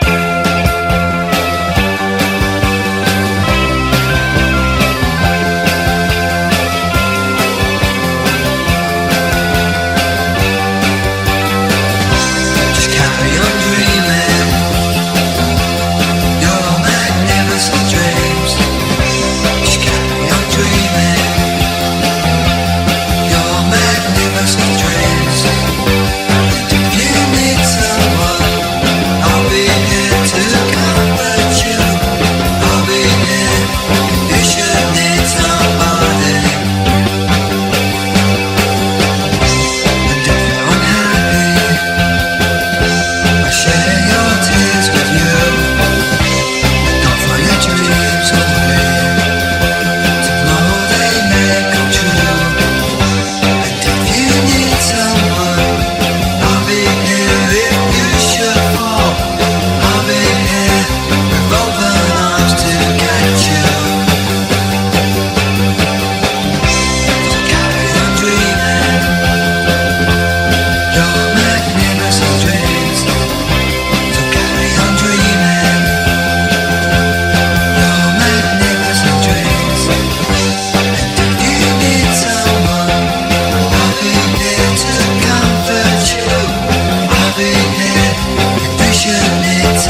ROCK / 60'S / VOCAL / OLDIES / RHYTHM & BLUES
ヒップでファンキーな66年アルバム！
ドカドカと乾いたドラムが弾けてホップする